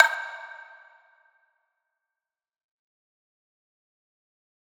Shameless Snare.wav